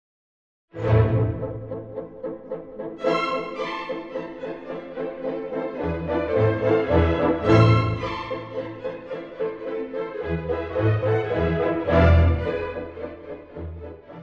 ↑古い録音のため聴きづらいかもしれません！（以下同様）
激しい波のような楽章です。
小節線・拍をまたぐスラーが特徴的です。
時には渦巻くように、時には勇壮に歌われつつ、最後の方で波が引いていきます。
波が完全に引くと、チェロによる長調が奏でられます。
そして最後はffの合奏により明るく終わるのです。